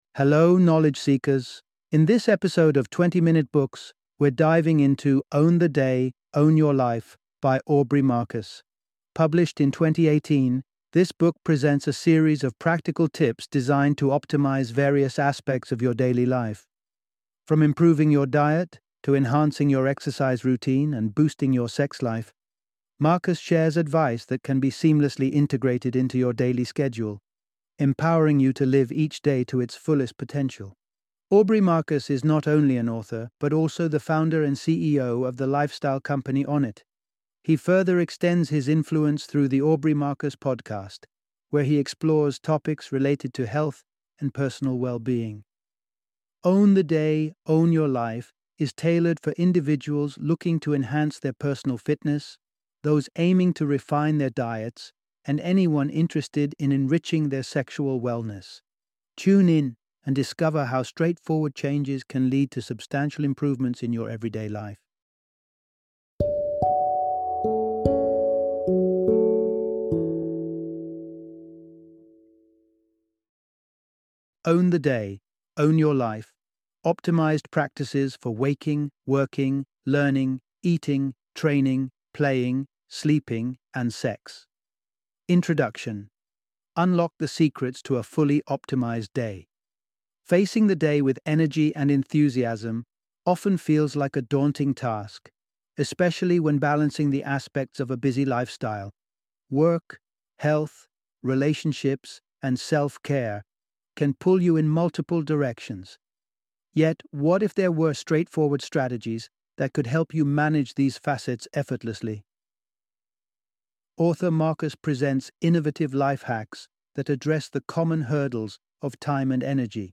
Own the Day, Own Your Life - Audiobook Summary